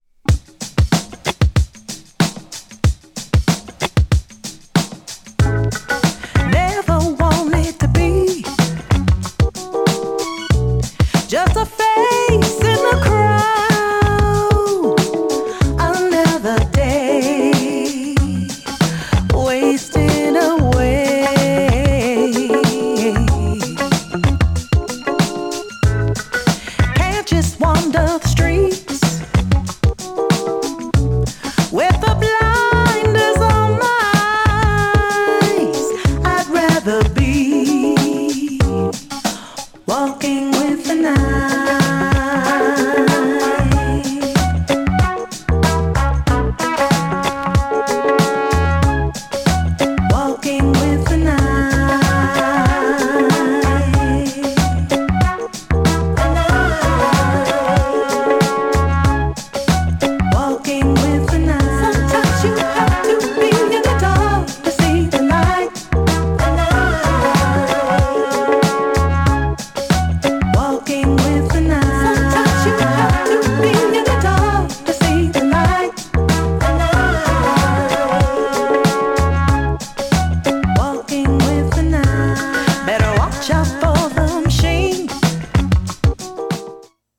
GENRE R&B
BPM 86〜90BPM